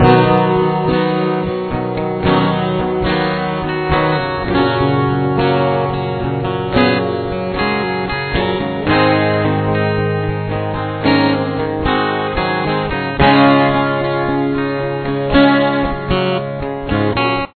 Here is the full intro :
The intro will have a few guitar parts.
other guitars are the fills in between the main rhythm part.